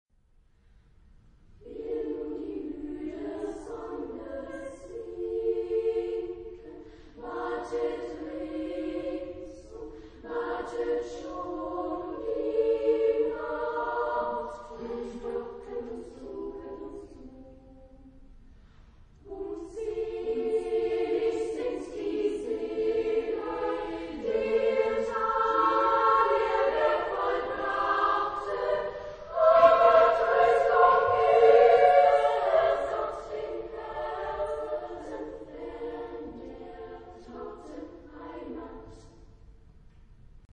Género/Estilo/Forma: Romántico ; Lied
Tipo de formación coral: SSA  (3 voces Coro femenino )
Tonalidad : fa mayor
Ref. discográfica: 7. Deutscher Chorwettbewerb 2006 Kiel